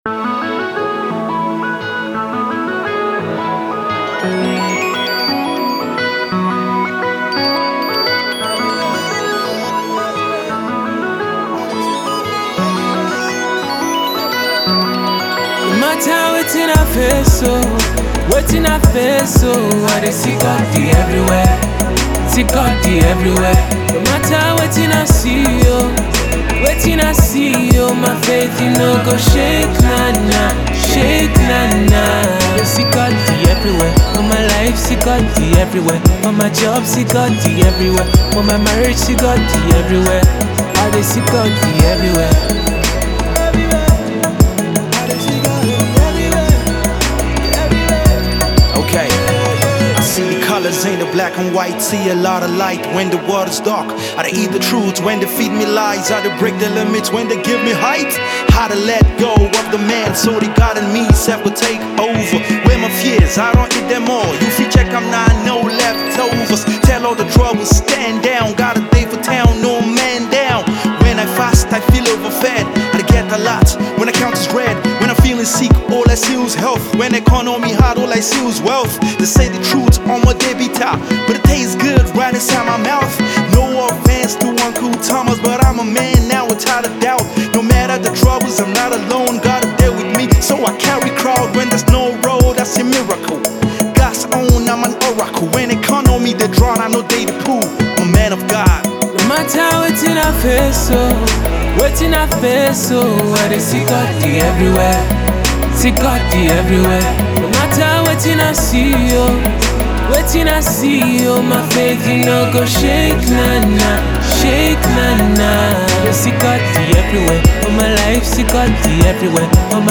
Gospel Rapper